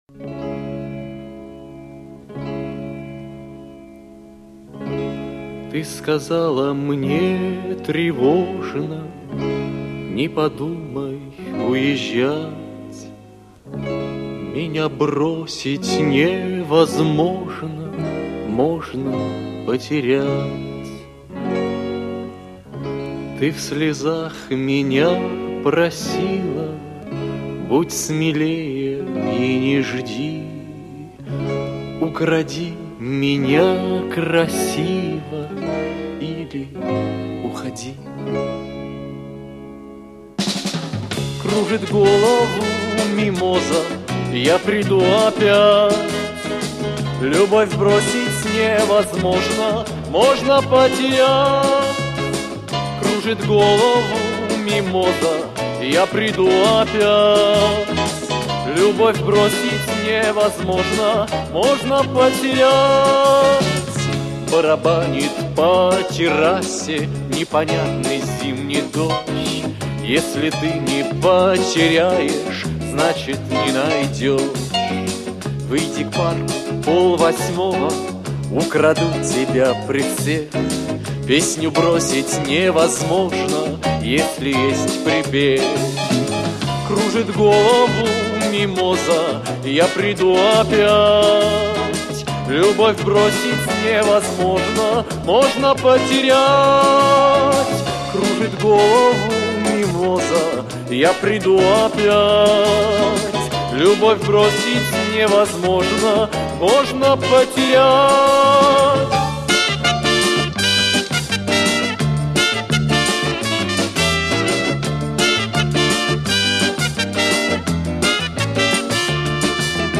отчетный концерт со стерео